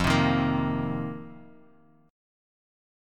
F7sus4 chord